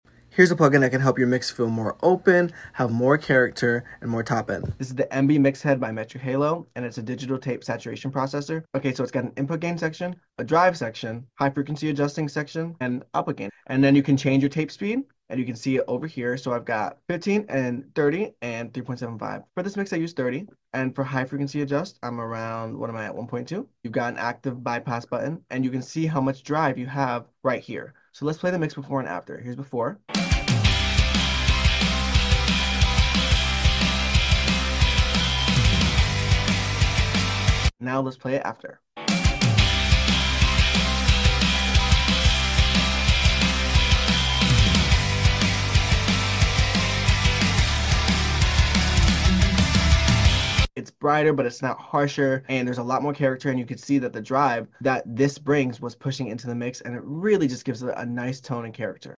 Here’s a plugin that can help your mix feel more open, add more character, and more top end.